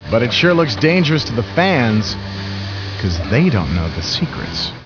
smarmy announcer, who called wrestling everything but real, as a bunch of hasbeens and wannabes in silly outfits “gave away” the business.